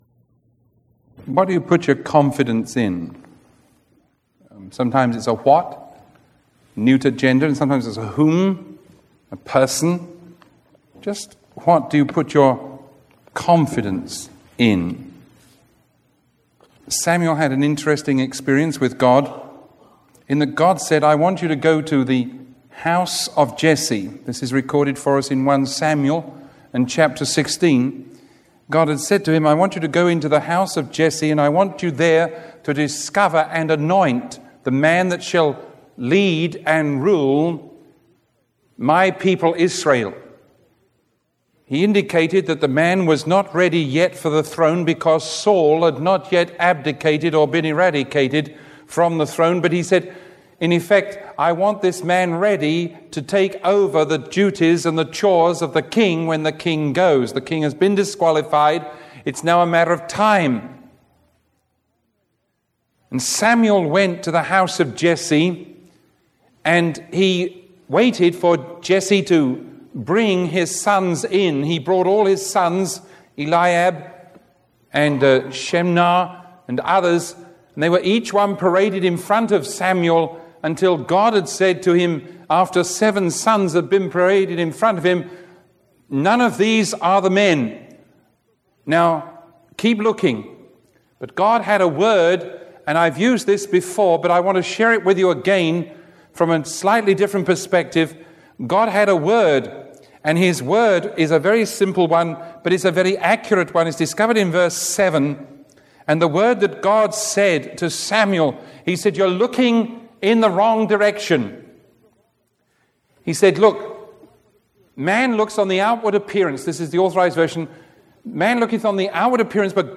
Sermon 0926B recorded on August 19